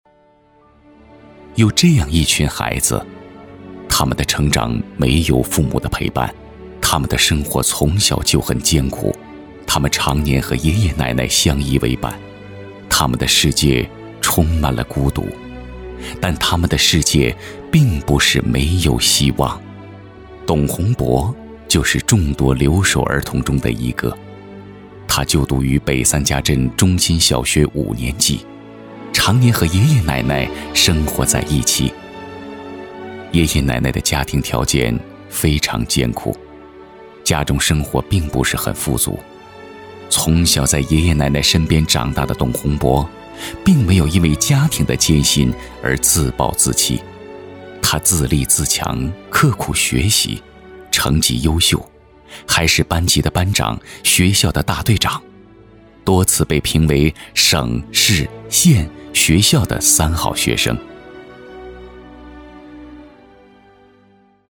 男国422厚重大气配音-新声库配音网
4 男国422_专题_政府_留守儿童专题片_讲述 男国422
男国422_专题_政府_留守儿童专题片_讲述.mp3